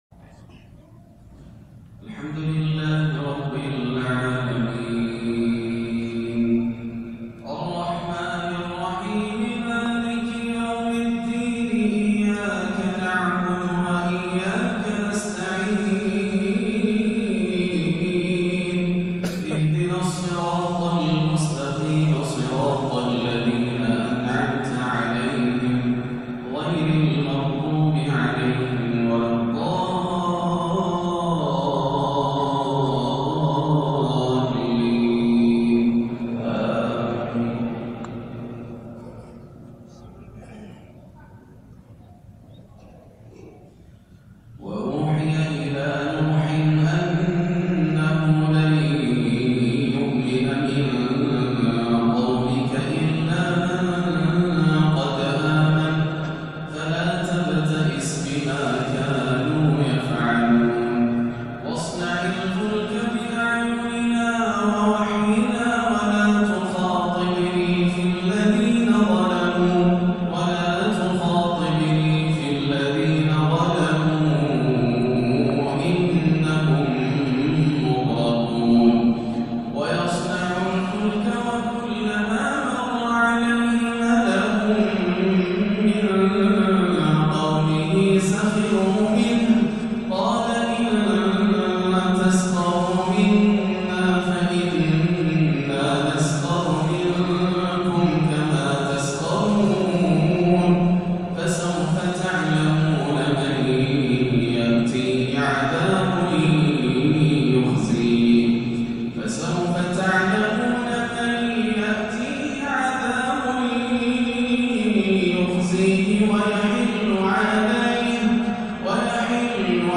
عشاء الإثنين 5-8-1438هـ من سورتي هود 36-49 و عبس > عام 1438 > الفروض - تلاوات ياسر الدوسري